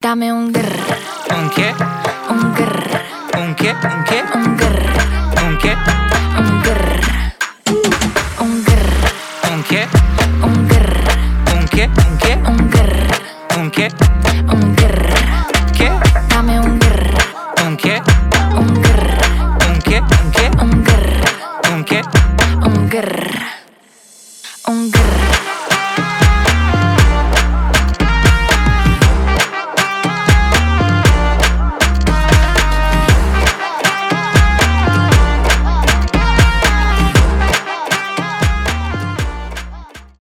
танцевальные
дуэт